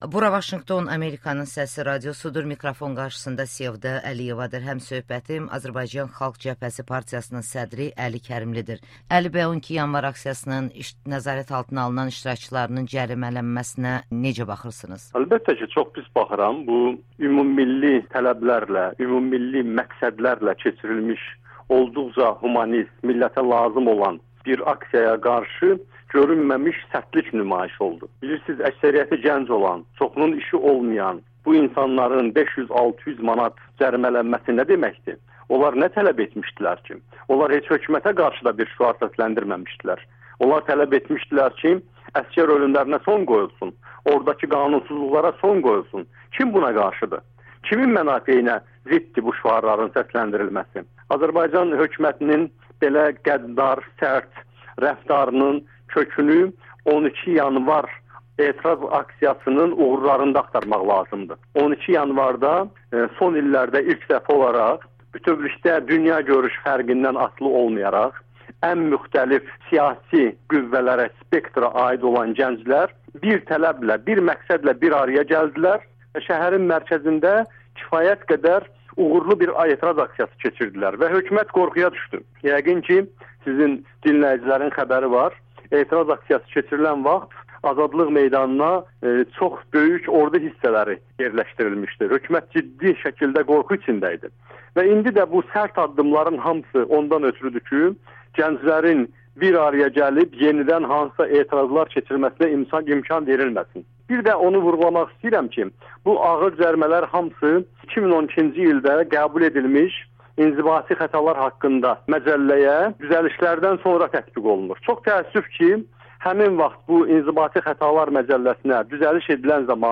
Əli Kərimli ilə müsahibə